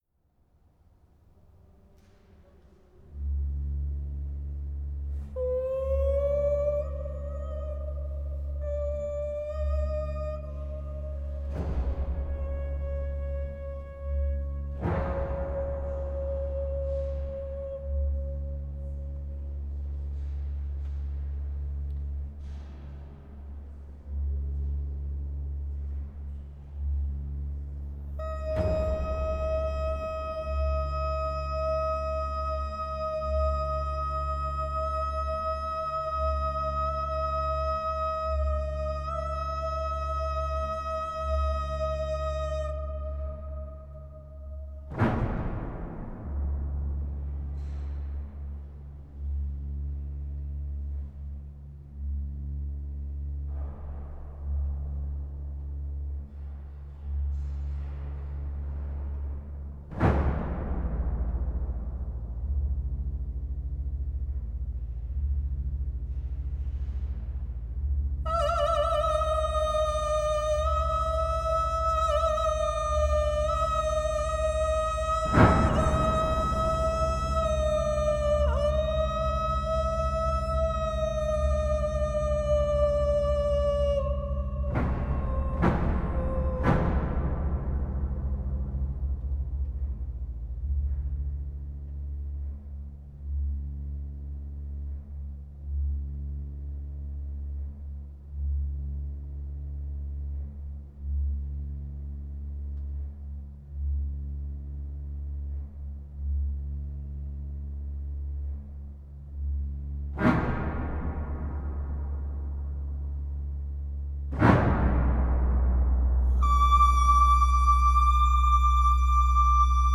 experimental music
Pipe organ
exquisite operatic singing
deep dark brooding pipe organ